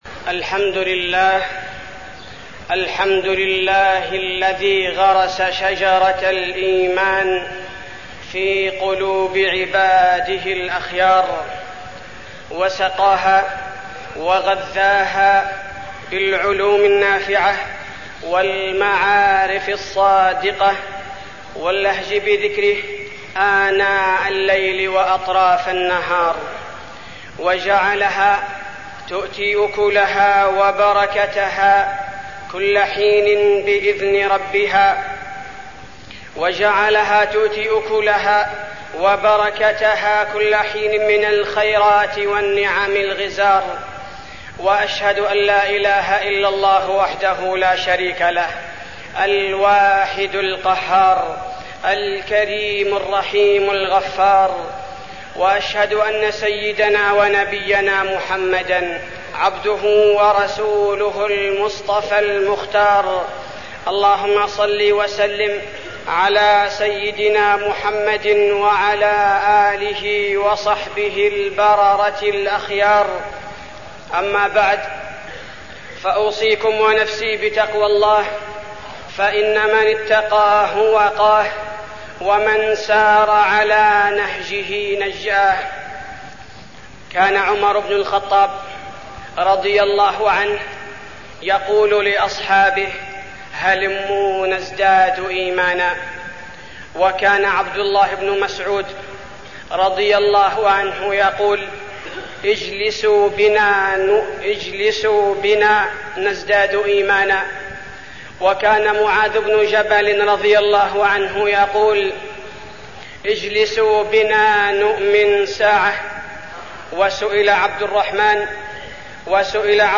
تاريخ النشر ١٧ محرم ١٤١٨ هـ المكان: المسجد النبوي الشيخ: فضيلة الشيخ عبدالباري الثبيتي فضيلة الشيخ عبدالباري الثبيتي الإيمان زيادته ونقصانه